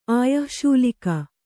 ♪ āyah śulika